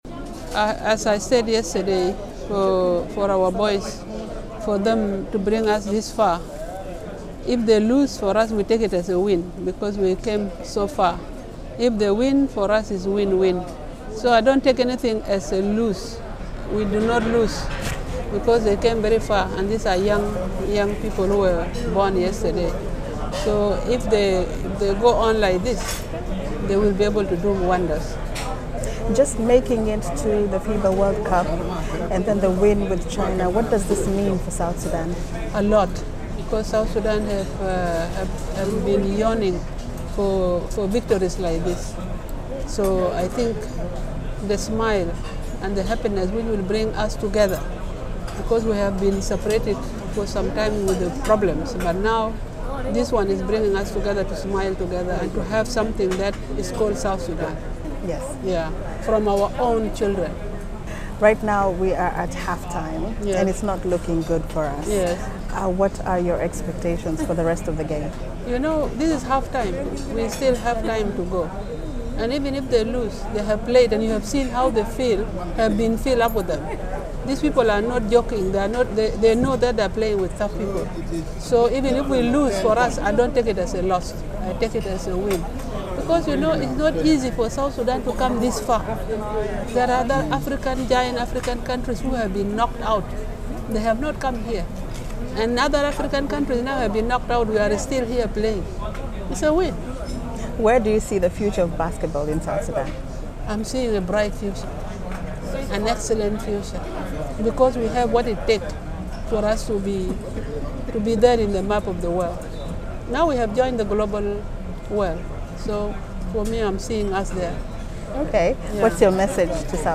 Interview with Her Excellency Mama Rebecca Nyandeng De Mabior Vice President Gender and Youth Cluster on her expectations of the team after Loss to Peurto Rrico in first Group stage game of the FIBA world Cup 2023.